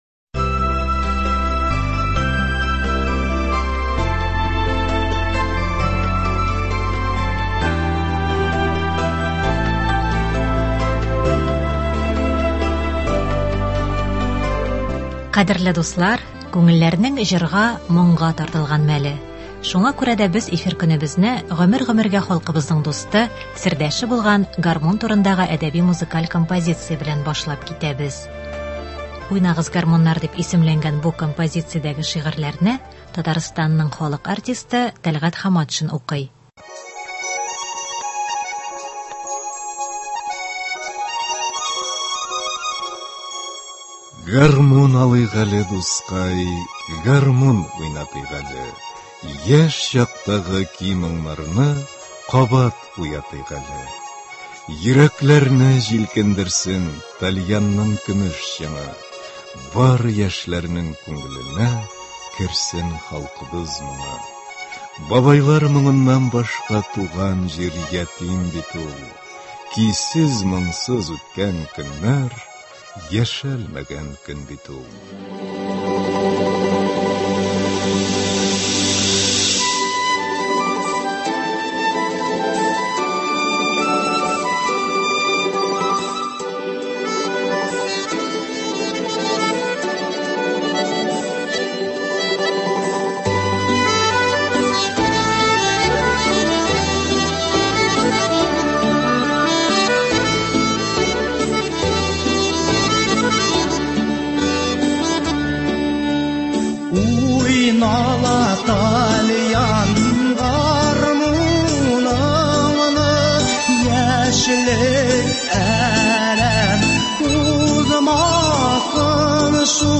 “Уйнагыз, гармуннар!” Әдәби-музыкаль композиция.